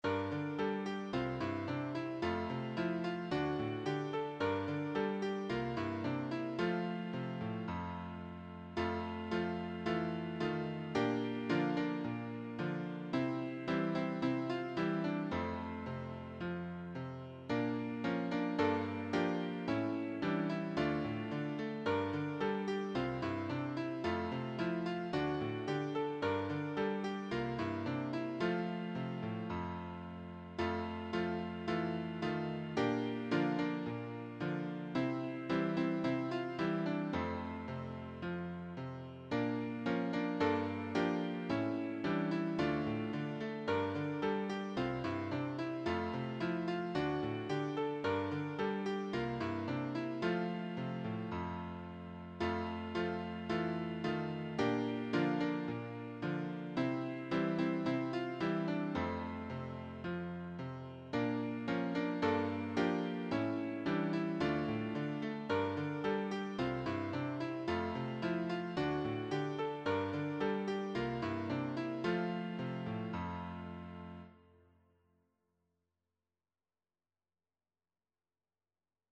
Deep in the belly of the whale (Piano Accompaniment)
Deep-in-the-belly-of-the-whalepianoacc.mp3